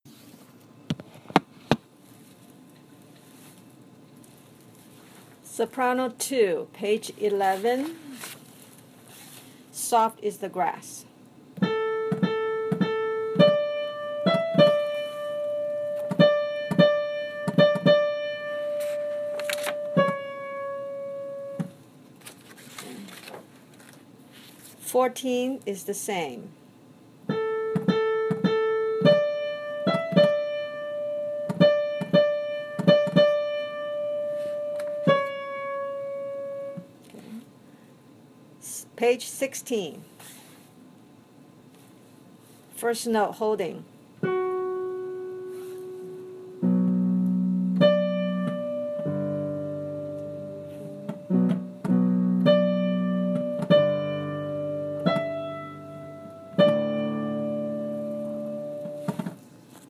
Carrickfergus Soprano 2.mp3